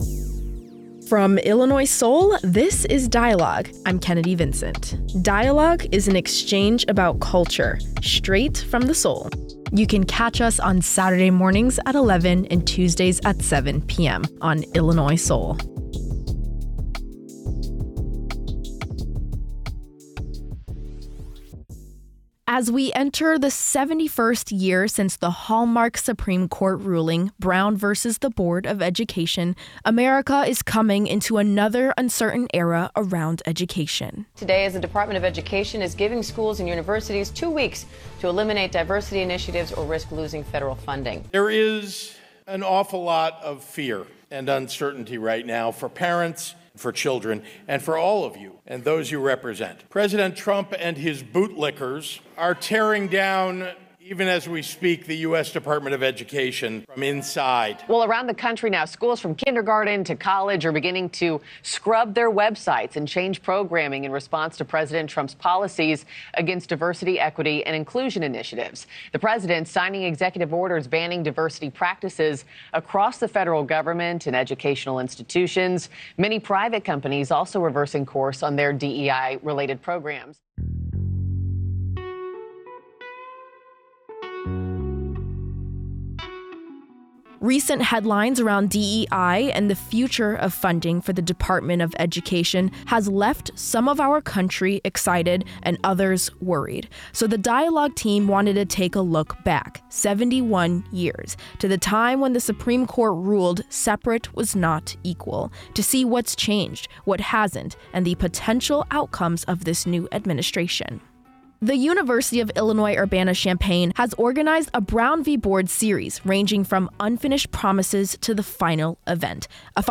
At a recent University of Illinois Urbana Champaign event, the College of Education and the Chancellor's Office hosted a fireside chat. The conversation was the last event in their year-long series on conversations around Brown vs. Board.